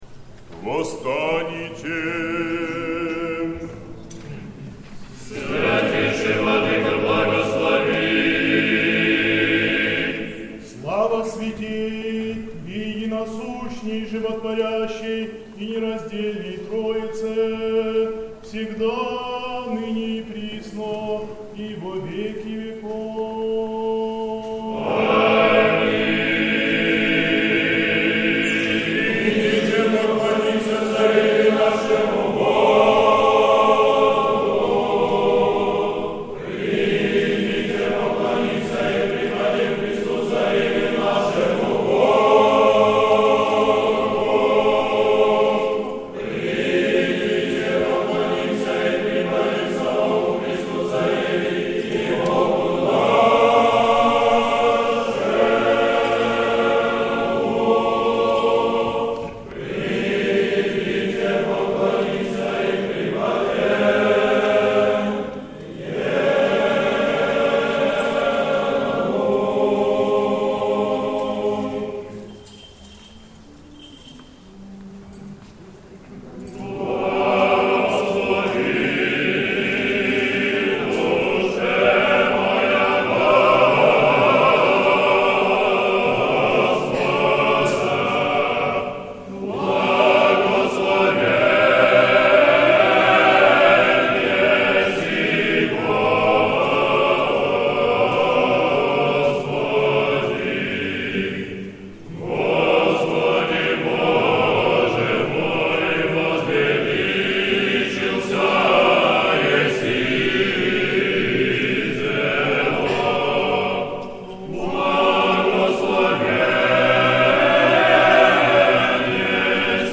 Сретенский монастырь. Великая вечерня, утреня. Хор Сретенского монастыря.
Всенощное бдение в Сретенском монастыре накануне Недели 29-й по Пятидесятнице